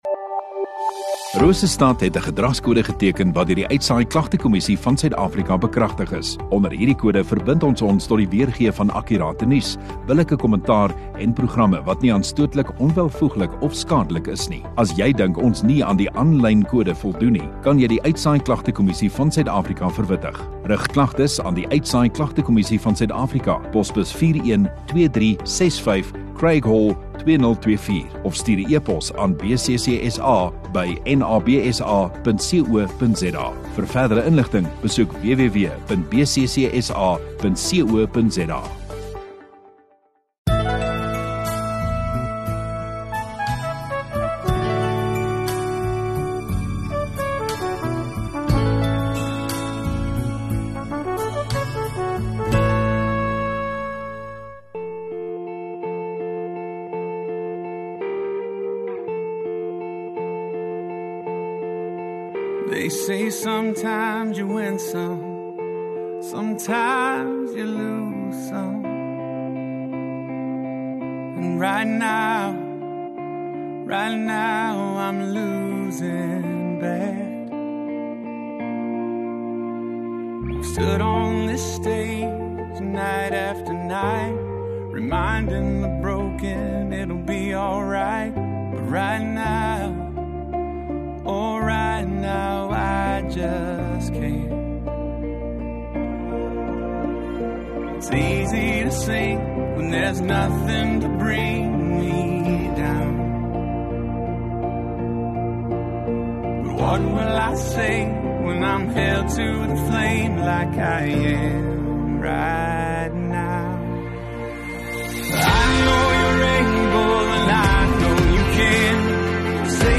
29 Sep Sondagoggend Erediens